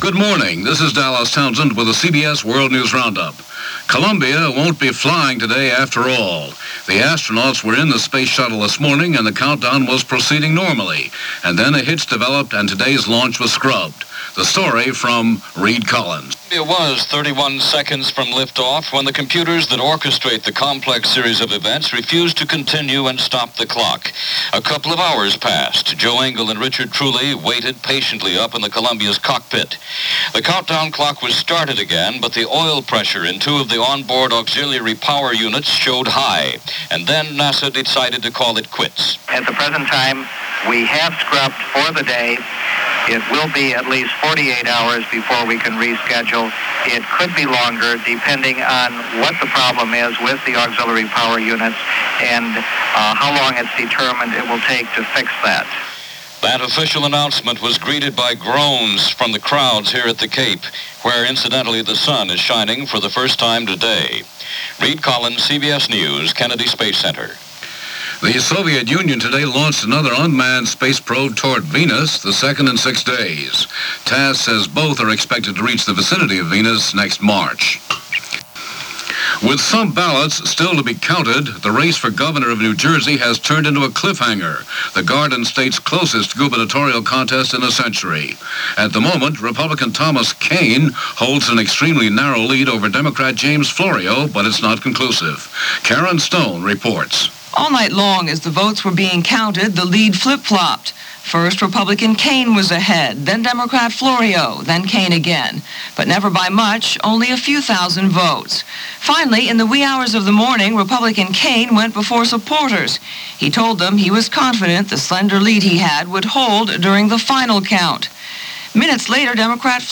November 4, 1981 – CBS World News Roundup – Newsbreak – 9:00 Network News – Gordon Skene Sound Collection –